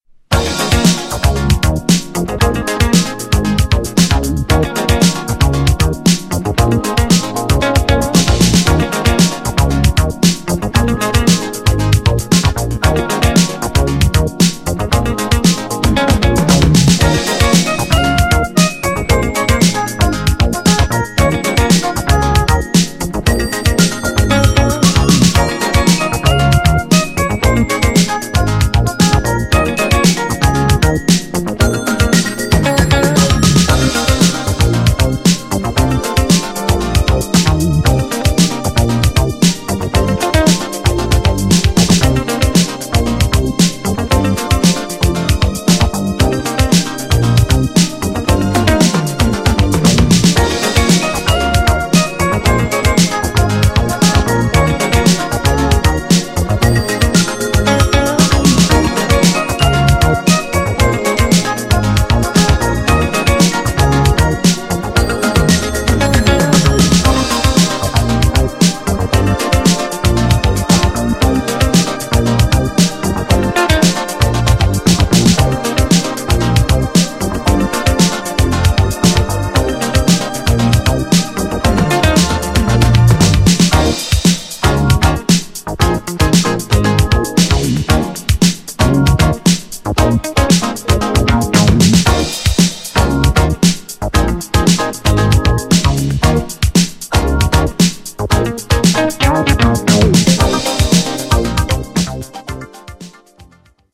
GENRE Dance Classic
BPM 116〜120BPM
EURO_DISCO # FUSION # INSTRUMENTAL # JAZZY
エレクトロ
バレアリック